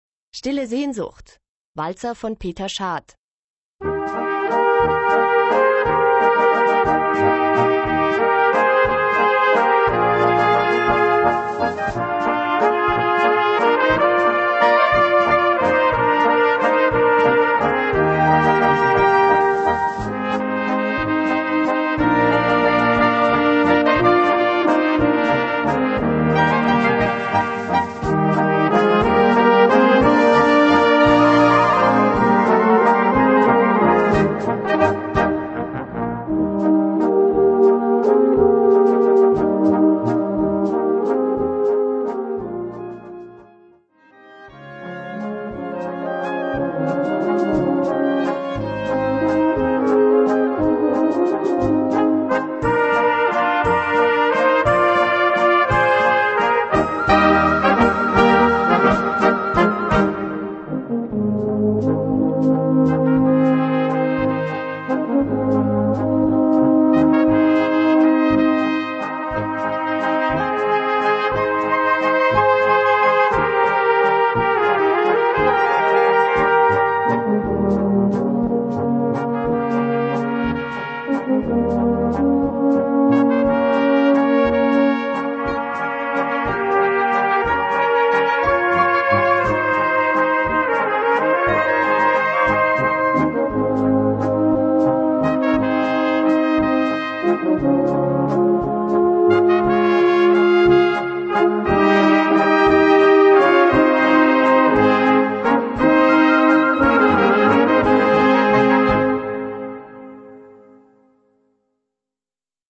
Gattung: Walzer
Besetzung: Blasorchester
Eine leichte, berührende Walzermelodie